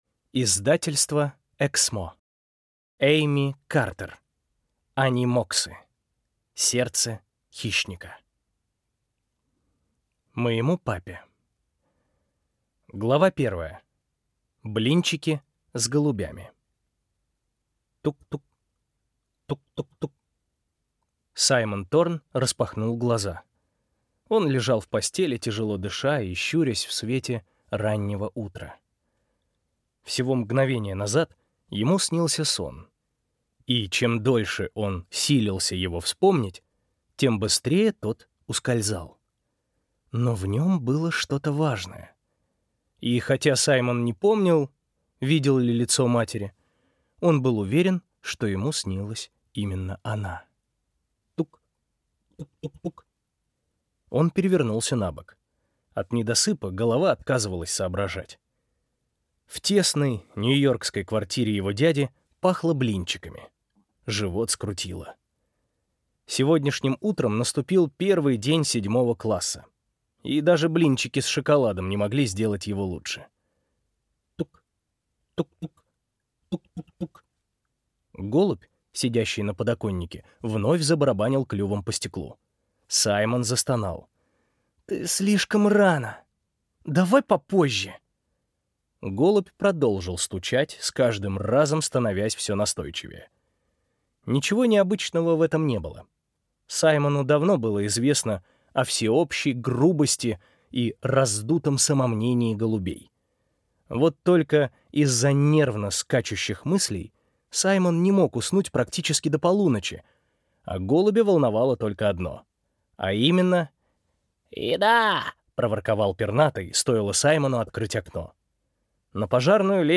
Аудиокнига Сердце Хищника | Библиотека аудиокниг